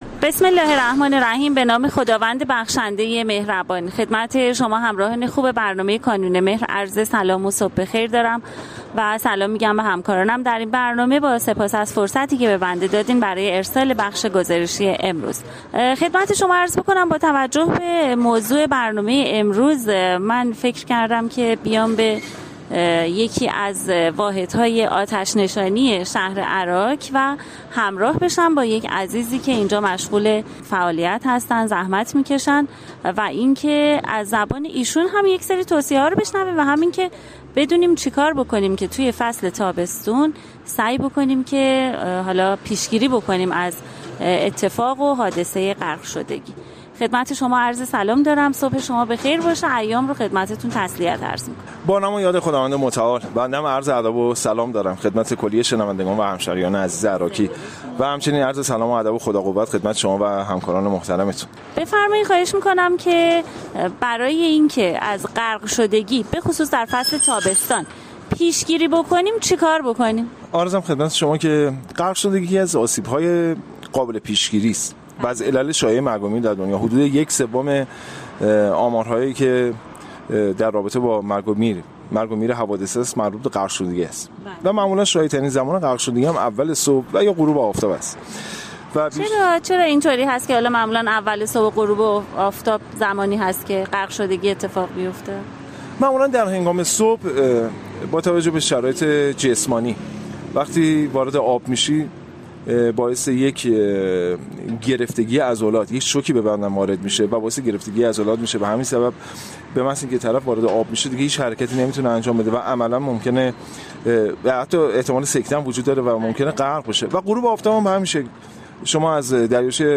توصیه های کارشناس آتش نشانی درباره پیشگیری از غرق شدگی